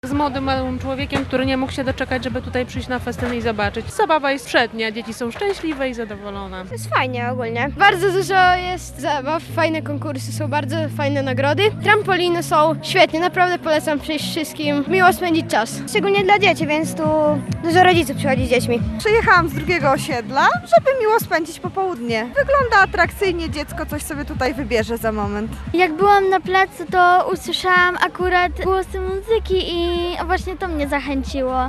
Pierwsza piknik połączony z koncertem odbywa się na lubelskim Czechowie.
– Jestem z młodym człowiekiem, który nie mógł się doczekać, by przyjść na festyn i zobaczyć. Dzieci są szczęśliwe i zadowolone – mówią mieszkańcy Lublina bawiący się na festynie.
mieszkancy_lublina-31.mp3